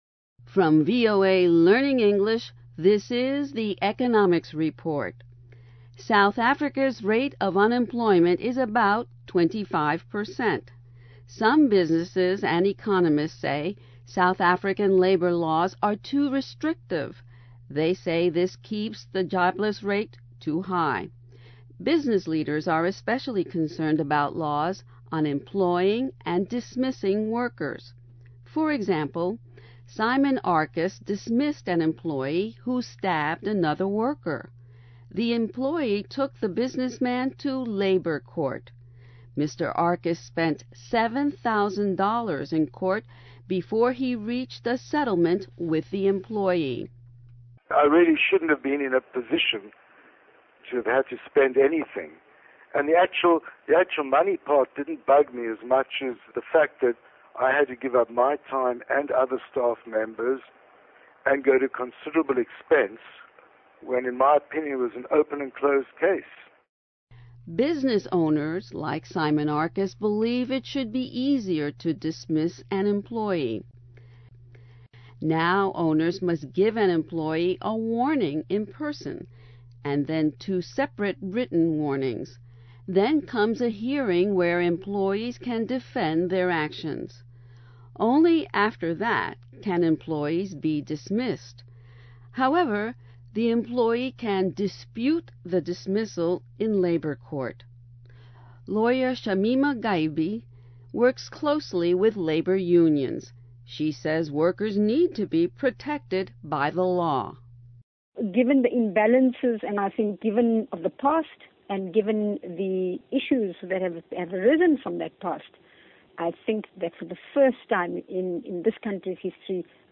VOA Special English > Economics Report > South African labor laws are too restrictive